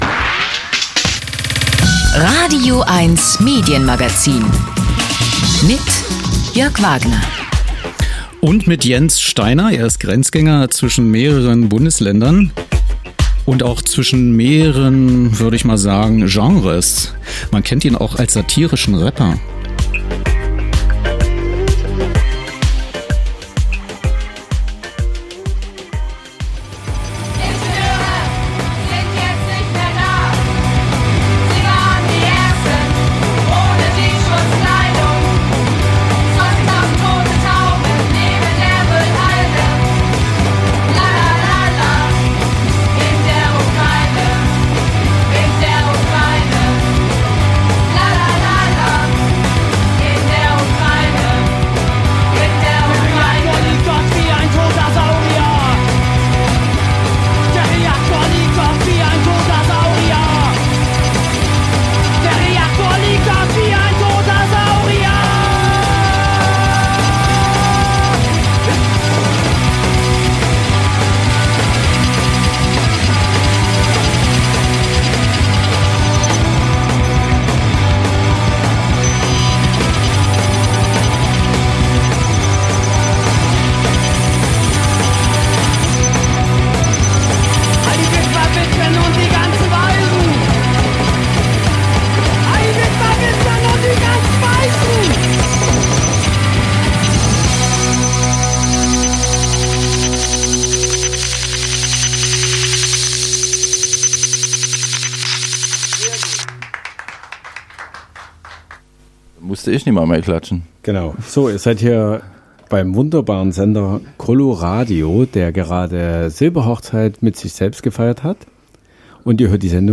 Studiogespräch mit O-Tönen/Interview
Medienstadt Babelsberg, radioeins-Sendestudio